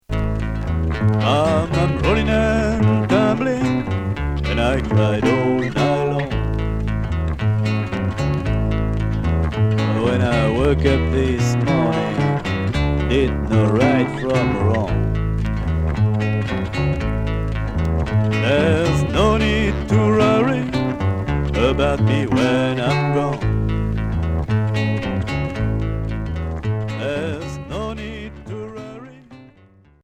Rock and folk